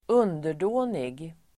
Ladda ner uttalet
underdånig adjektiv, humble Uttal: [²'un:der_då:nig] Böjningar: underdånigt, underdåniga Definition: (alltför) ödmjuk (subservient, (far too) obedient) Exempel: en underdånig tjänare (a humble servant)